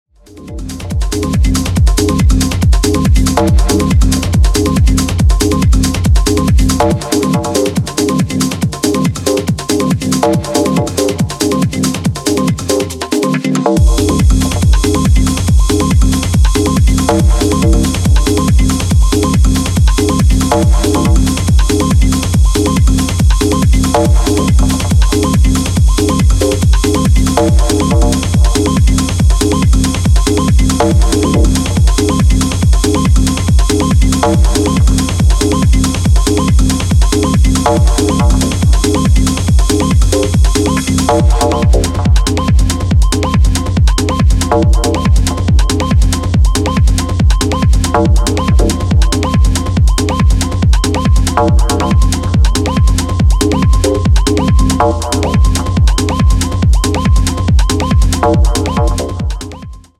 Techno